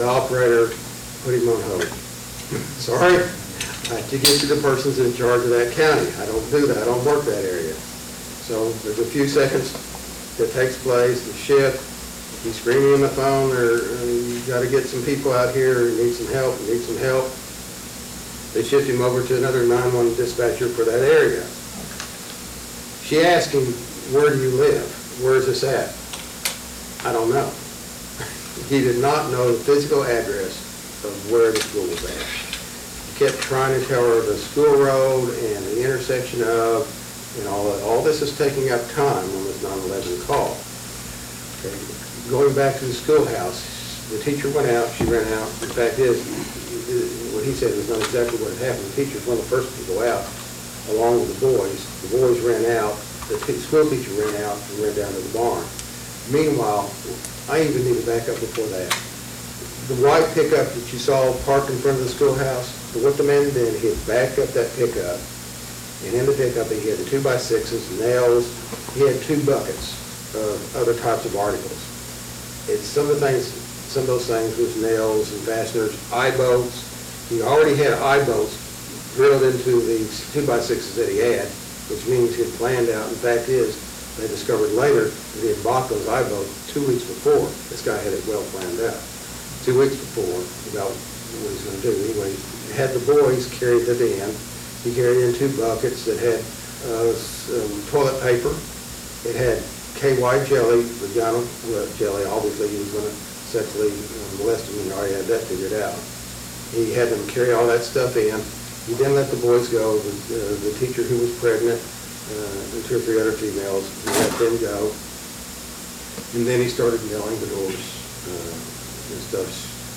church-security-workshop-session-6.mp3